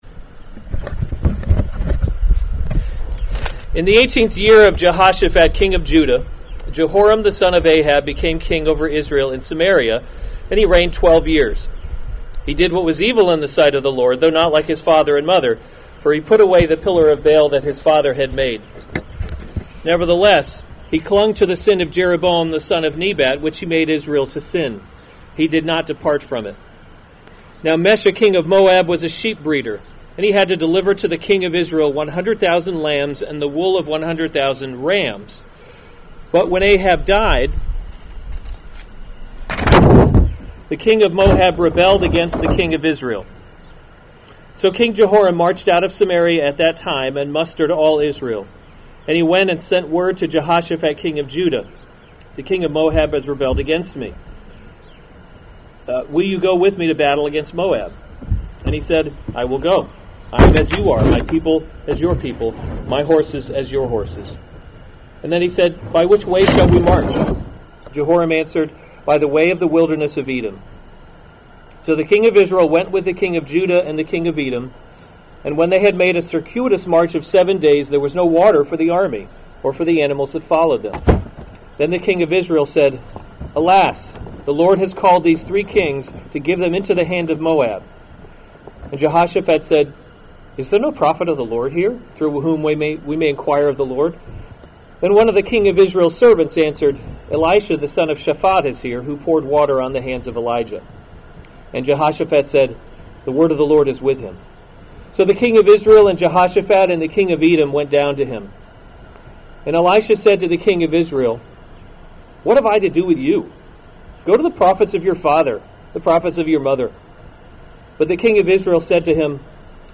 March 7, 2021 2 Kings – A Kingdom That Cannot Be Shaken series Weekly Sunday Service Save/Download this sermon 2 Kings 3:1-27 Other sermons from 2 Kings Moab Rebels Against […]